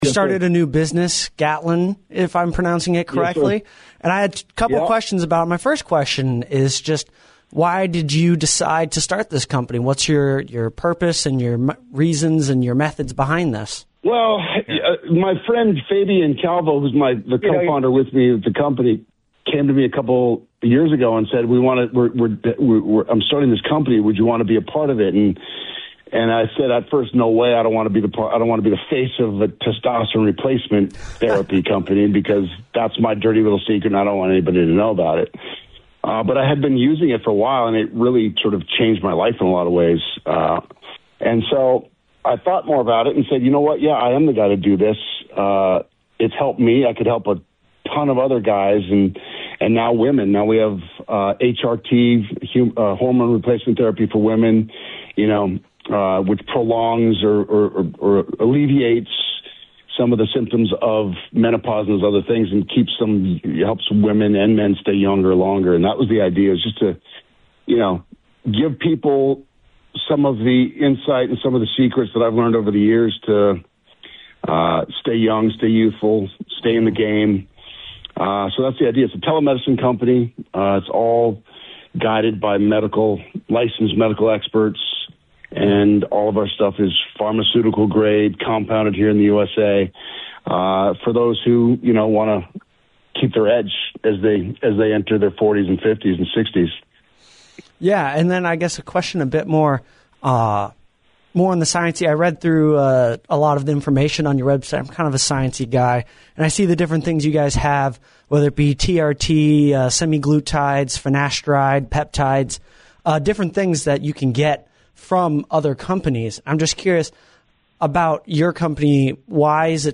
LISTEN: Josh Duhamel on “What’s On Your Mind?”